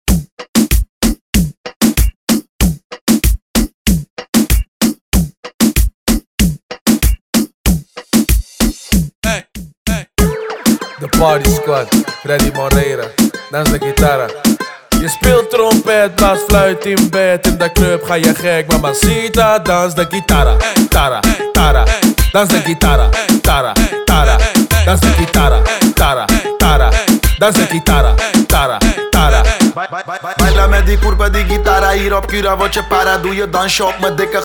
His style is unique and electrifying.